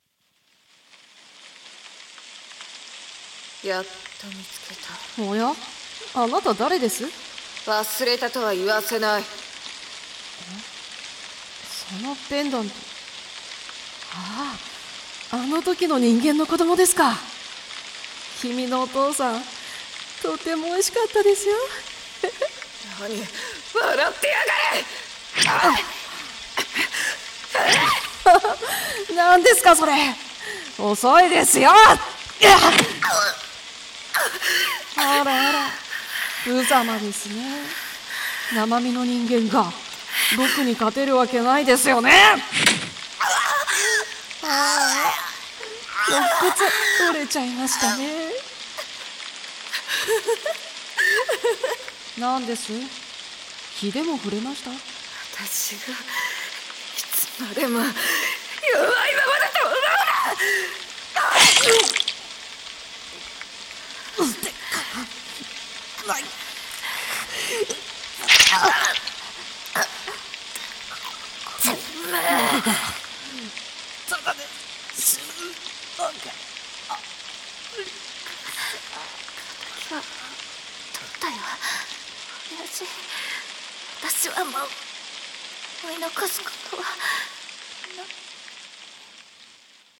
声劇